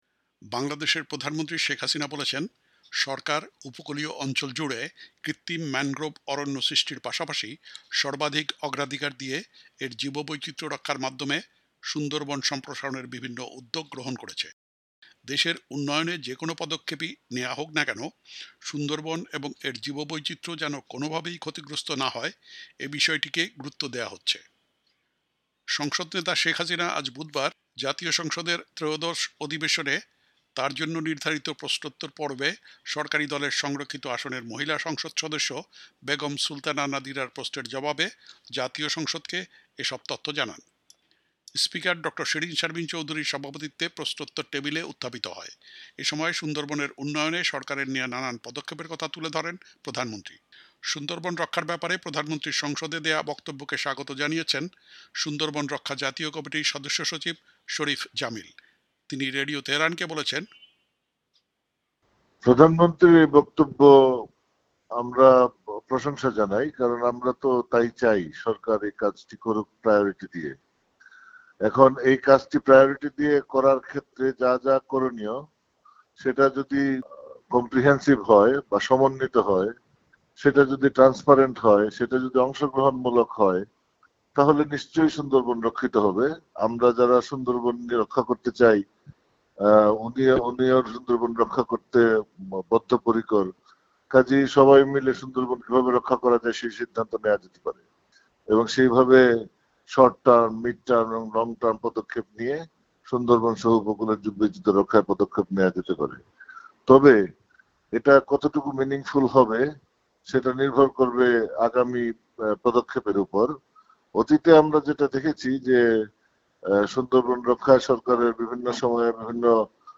জীববৈচিত্র্য রক্ষার মাধ্যমে সুন্দরবন সম্প্রসারণে সরকারের বিভিন্ন উদ্যোগ: বিশ্লেষক প্রতিক্রিয়া